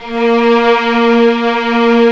55m-orc07-A#2.wav